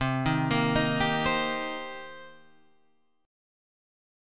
C Minor 7th